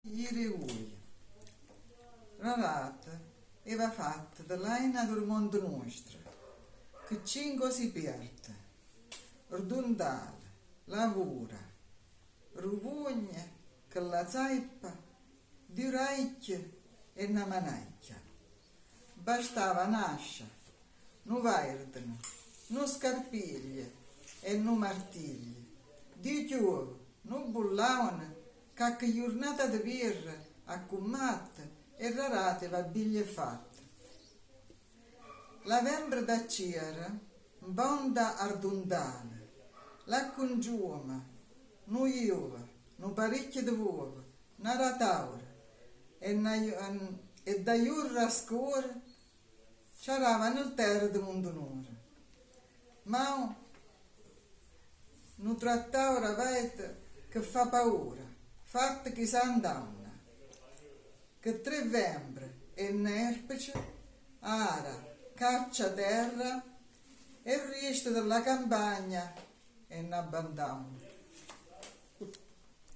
Il gruppo che recita le poesie in dialetto e che canta le due canzoni è composto da